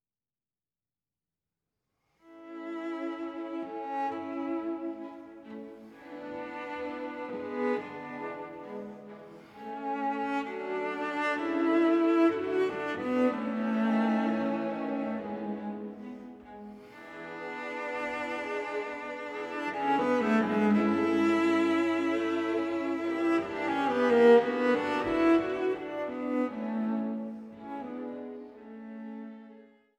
Violoncello